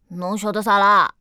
c01_3残疾小孩_2.wav